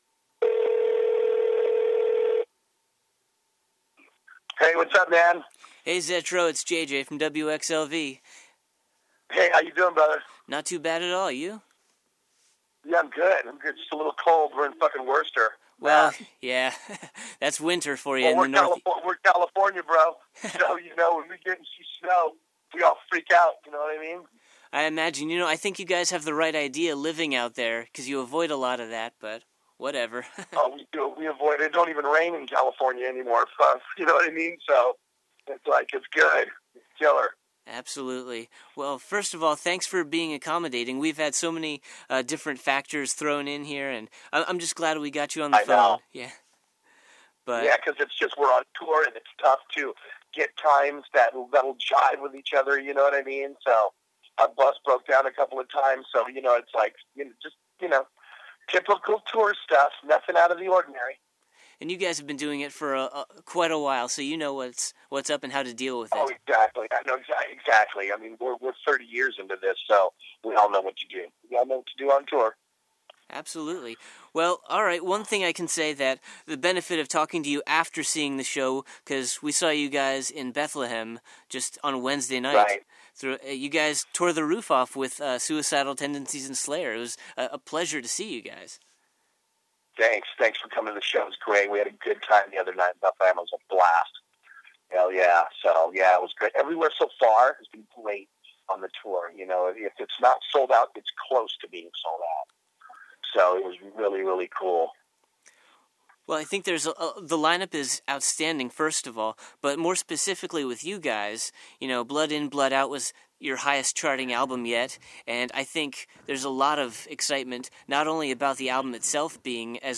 Exclusive: Slayer at the SBEC Plus an Exclusive Interview with Steve “Zetro” Souza of Exodus
55-interview-exodus.mp3